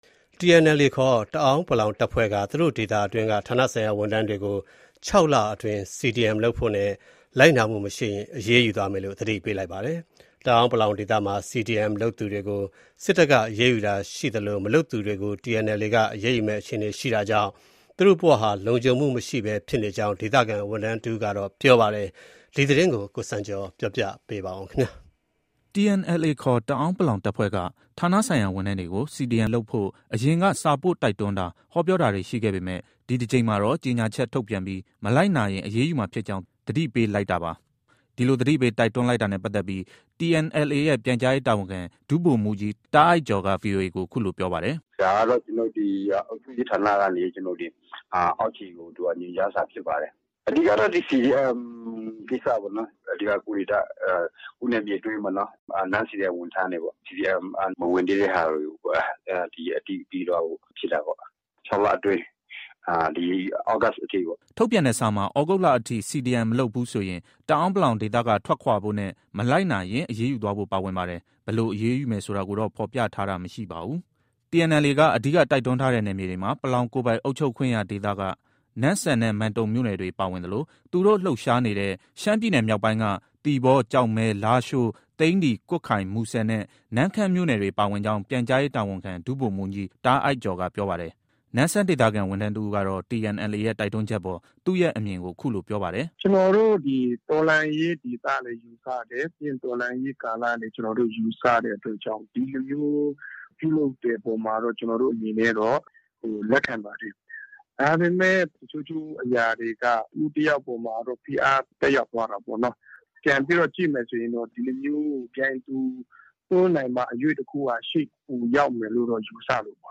TNLA ခေါ် တအန်း(ပလောင်) တပ်ဖွဲ့က သူတို့ဒေသတွင်းက ဌာနဆိုင်ရာဝန်ထမ်းတွေကို ၆လအတွင်း CDM လုပ်ဖို့နဲ့ လိုက်နာမှုမရှိရင် အရေးယူသွားမယ်လို့ သတိပေးလိုက်ပါတယ်။ တအန်း(ပလောင်)ဒေသမှာ CDM လုပ်သူတွေကို စစ်တပ်က အရေးယူတာရှိသလို မလုပ်သူတွေကို TNLA က အရေးယူမယ့်အခြေအနေ ရှိတာကြောင့် သူတို့ဘဝဟာ လုံခြုံမှုမရှိဘဲဖြစ်နေကြောင်း ဒေသခံဝန်ထမ်းတစ်ဦးက ပြောပါတယ်။
နမ့်ဆန်ဒေသခံ ဝန်ထမ်းတစ်ဦးကတော့ TNLA ရဲ့ တိုက်တွန်းချက်အပေါ် သူ့ရဲ့အမြင်ကို အခုလို ပြောပါတယ်။